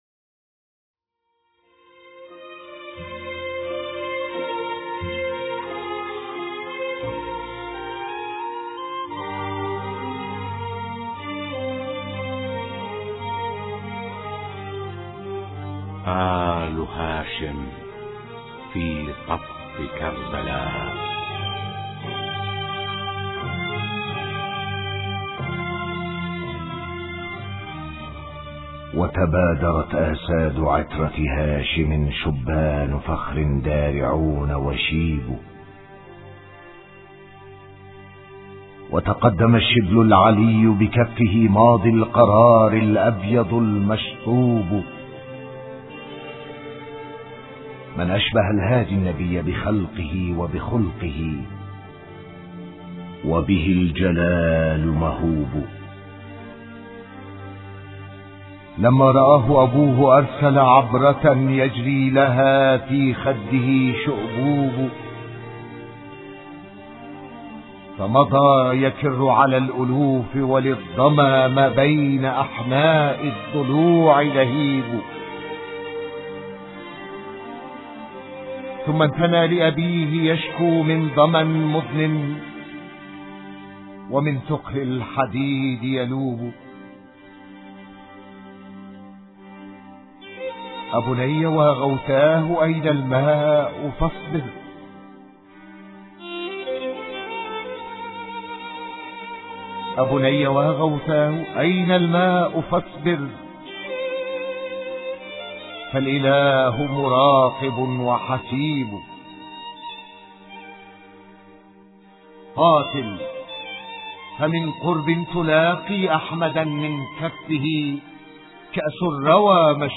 نحن نلتقيكم في حلقة اخرى من حلقات برنامج آل هاشم في طف كربلاء، وحديثنا فيه عن قصة استشهاد فتى الحسين علي الاكبر عليهما السلام وقد استمعتم اليها مجملة بلغة الشعر في الابيات الرقيقة التي قرأناها لكم آنفاً .
نستمع عبر الهاتف الى نبذة من مقامات مولانا علي الاكبر سلام الله عليه في النصوص الشريفة .